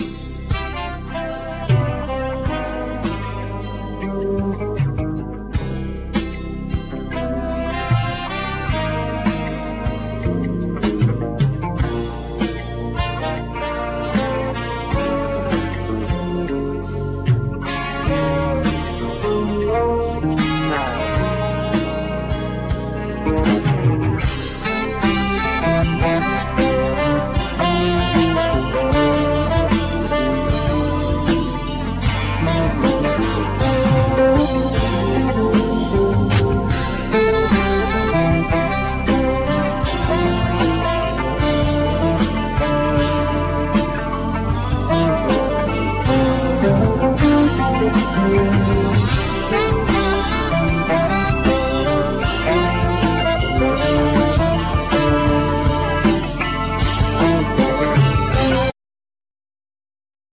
Alto sax,Synthesizer
Keyboards
Guitar
Bass
Tenor sax
Drums
Percussions